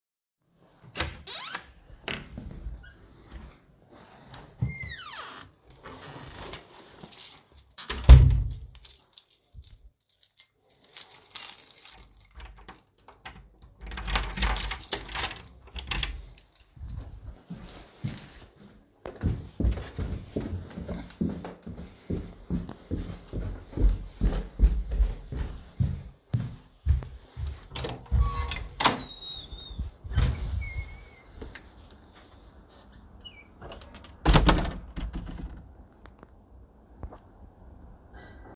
appartment-to-outside-door-26825.mp3